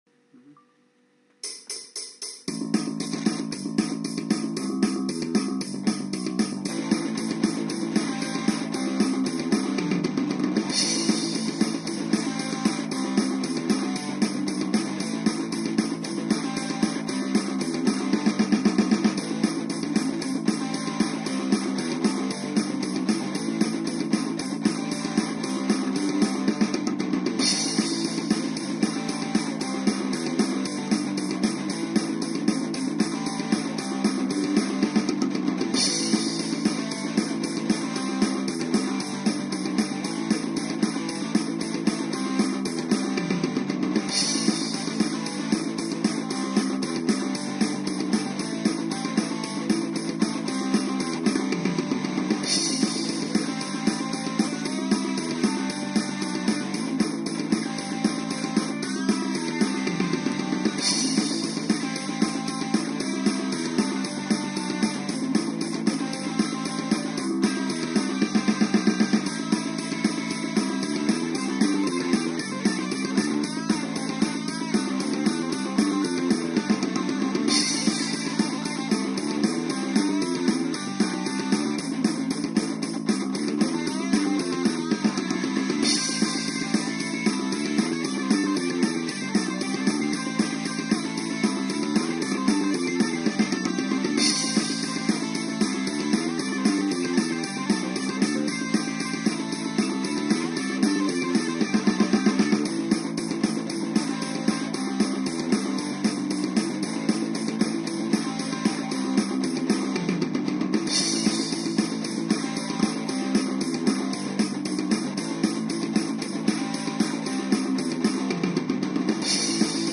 Громко не включать! Записано на телефон!